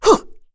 Cecilia-Vox_Attack1_kr.wav